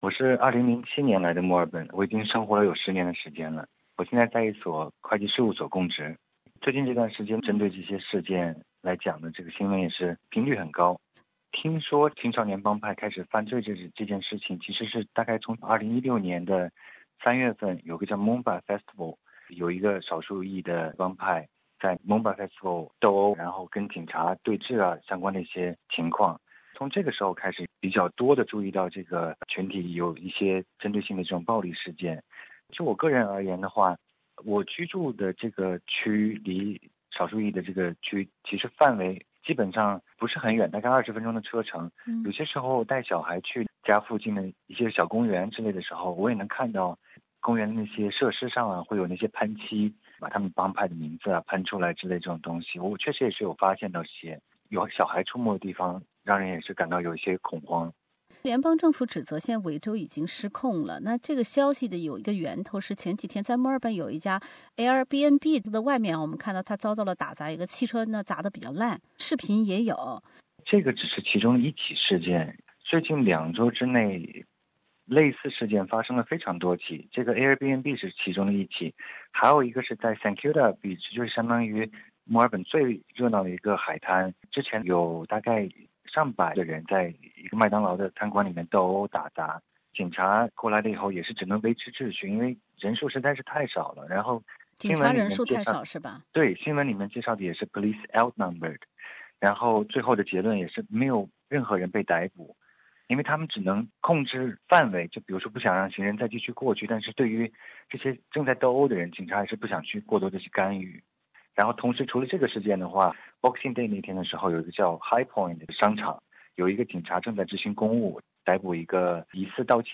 There have been calls for more Australian Federal Police officers to deal with the issue of youth gangs in Melbourne suburbs. But Victoria Police have denied youth crime is associated with any particular ethnic group. Interview